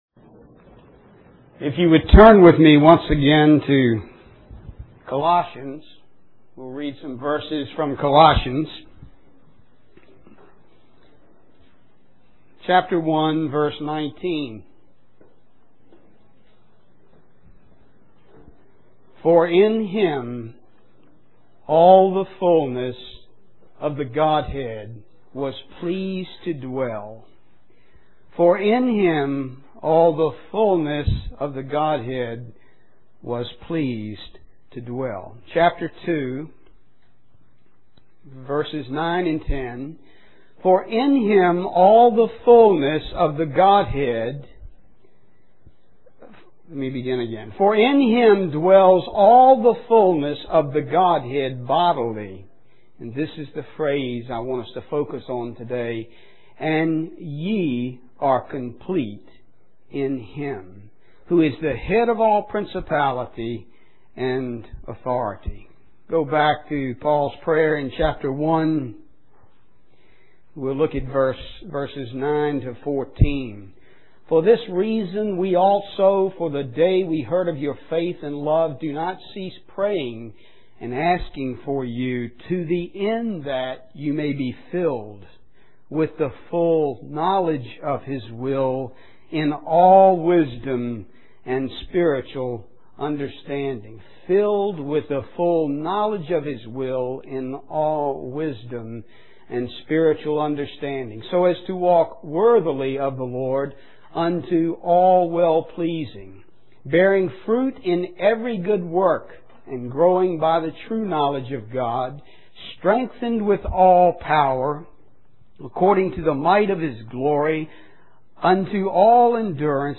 Richmond, Virginia, US